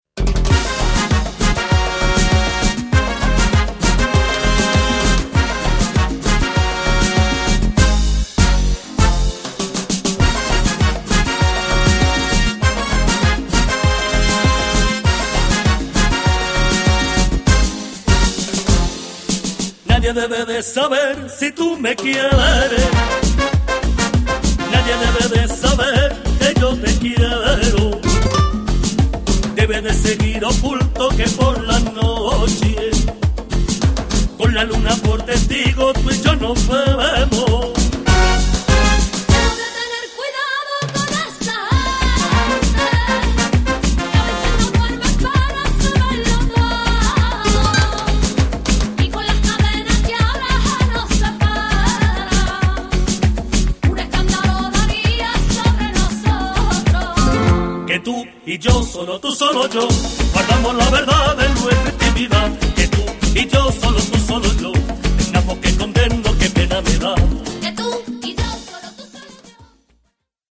最初から最後まで、彼らのルーツ音楽である『ルンバ・フラメンコ』に対する敬意・愛が溢れている！